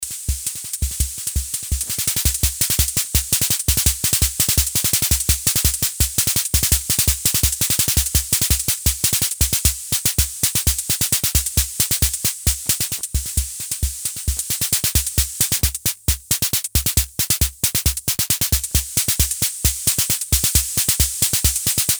Basic Italian analog drum machine with patterns presets styles and no sync option.
congo bongo2 snare cymbal
bongo1 claves maracas kick drum